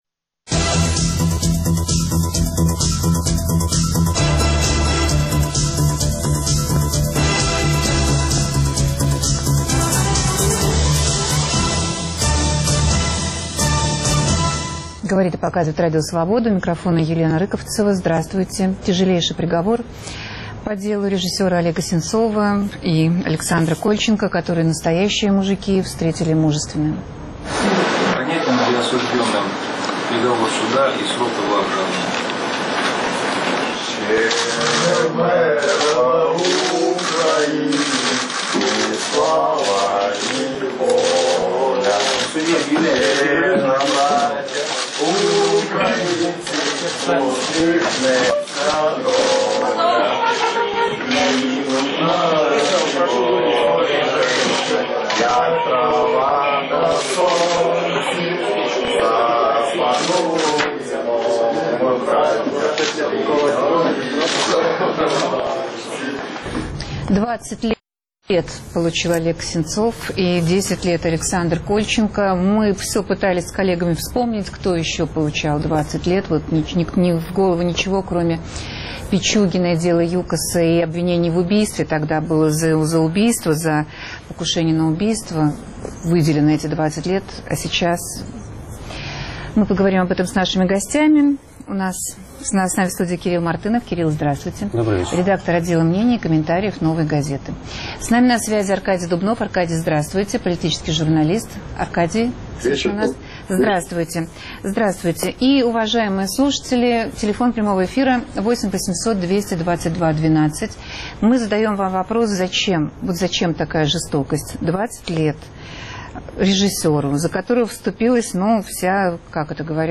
Беспрецедентно жестокий приговор по делу режиссера Олега Сенцова и переговоры в Берлине между президентами Украины, Франции и Германии без участия Владимира Путина. В студии